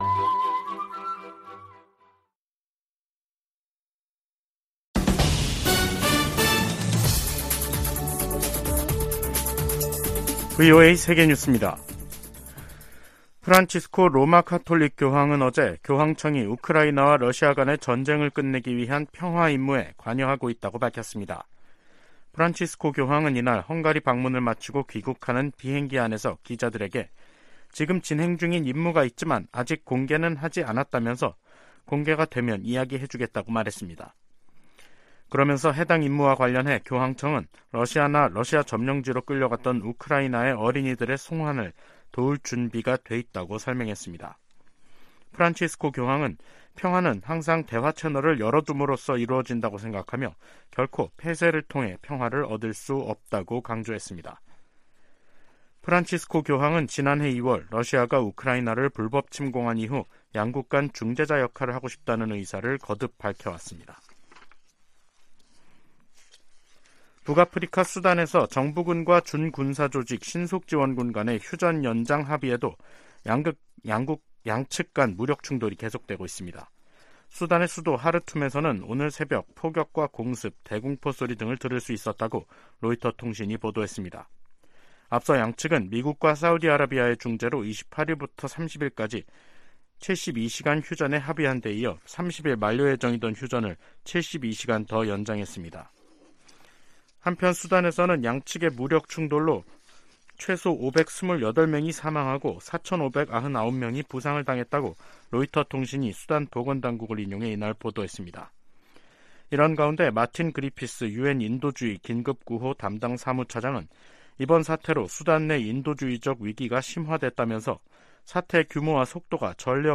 VOA 한국어 간판 뉴스 프로그램 '뉴스 투데이', 2023년 5월 1일 2부 방송입니다. 김여정 북한 노동당 부부장이 ‘결정적 행동’을 언급해 대형 도발에 나설 가능성을 시사했습니다. 윤석열 한국 대통령이 하버드대 연설에서 워싱턴 선언에 포함된 한국의 의무를 거론하며 독자 핵개발에 선을 그었습니다. 12년 만에 이뤄진 한국 대통령의 미국 국빈 방문은 한층 강화된 양국 관계를 보여줬다고 캐서린 스티븐스 전 주한 미국대사가 평가했습니다.